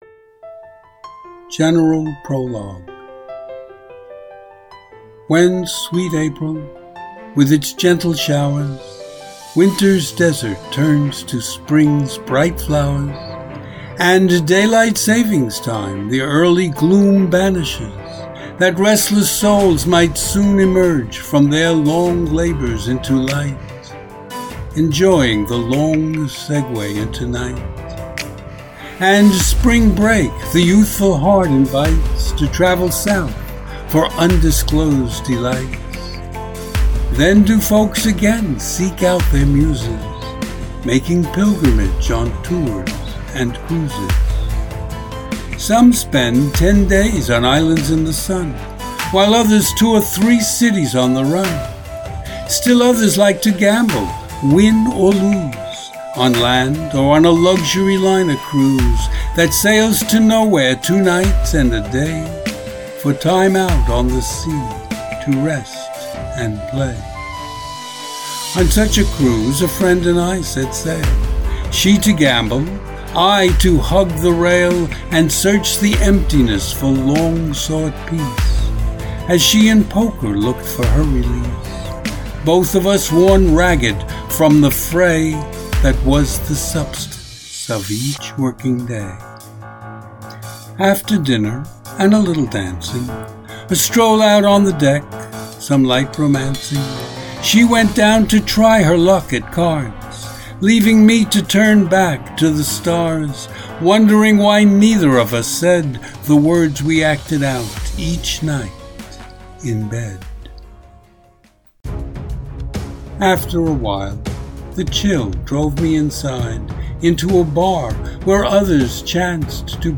Hear me read the General Prologue as an MP3 file.